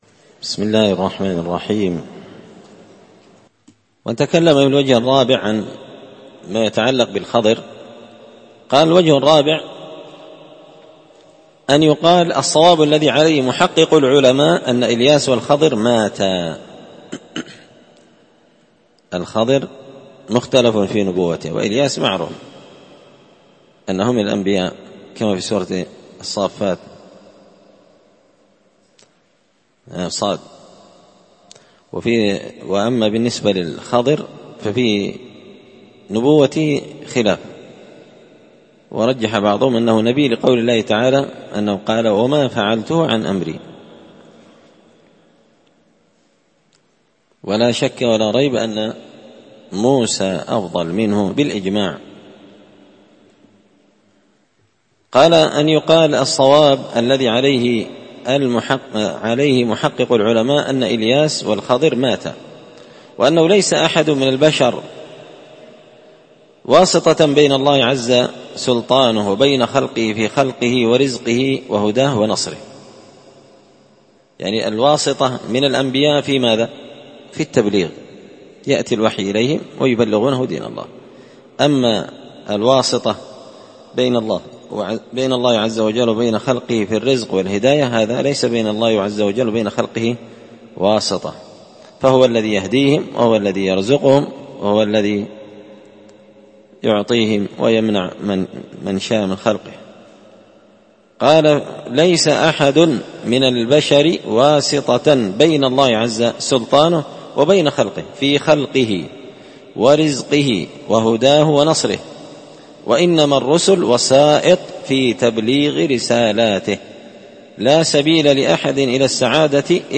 الدرس الثامن عشر (18) فصل لاوجود لإلياس والخضر
مسجد الفرقان قشن_المهرة_اليمن